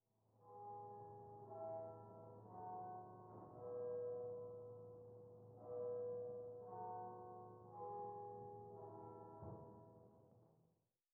01_院长房间_屋外钟声.wav